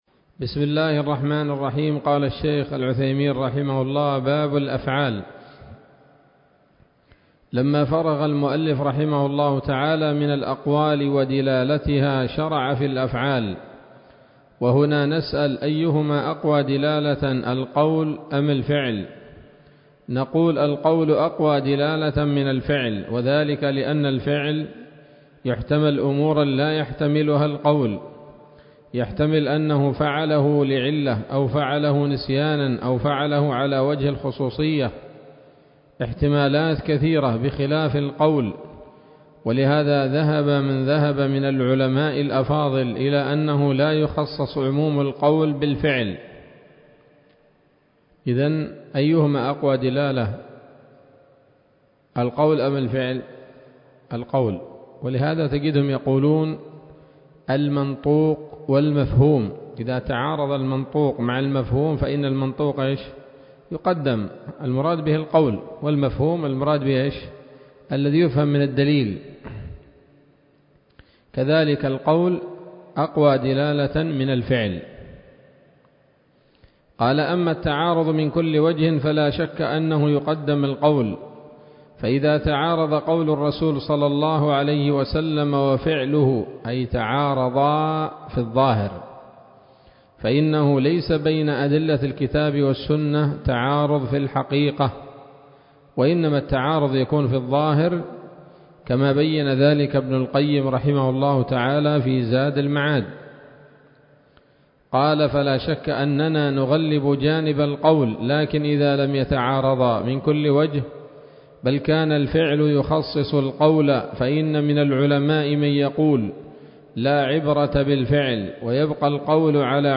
الدرس السادس والأربعون من شرح نظم الورقات للعلامة العثيمين رحمه الله تعالى